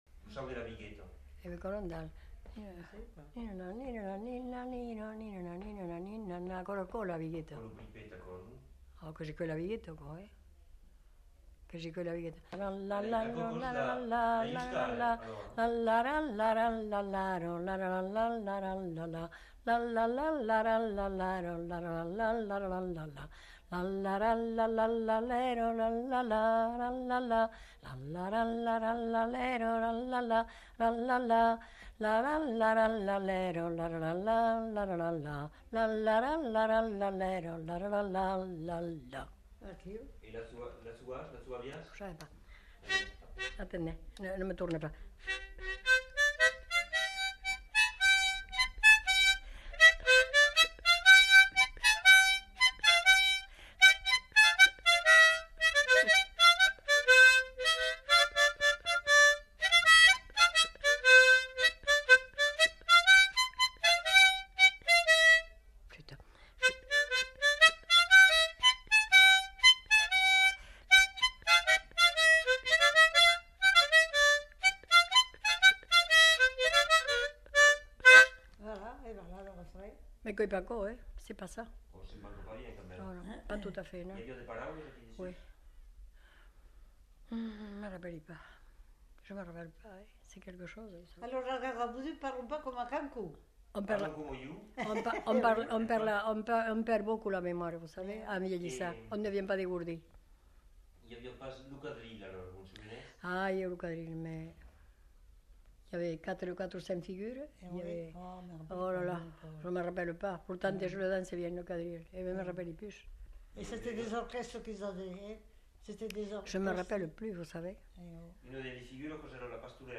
Aire culturelle : Haut-Agenais
Lieu : Cancon
Genre : chant
Effectif : 1
Type de voix : voix de femme
Production du son : fredonné
Danse : youska
Notes consultables : La youska est ensuite jouée à l'harmonica.